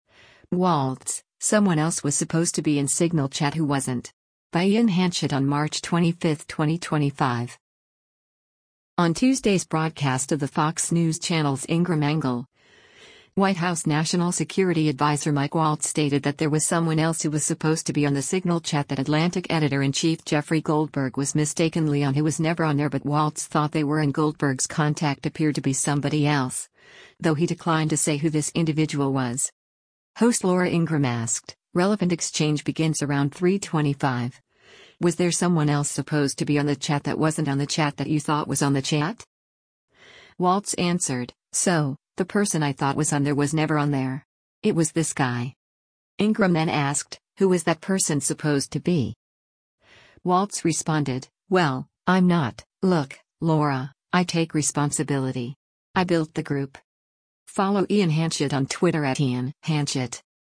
On Tuesday’s broadcast of the Fox News Channel’s “Ingraham Angle,” White House National Security Adviser Mike Waltz stated that there was someone else who was supposed to be on the Signal chat that Atlantic Editor-in-Chief Jeffrey Goldberg was mistakenly on who “was never on there” but Waltz thought they were and Goldberg’s contact appeared to be somebody else, though he declined to say who this individual was.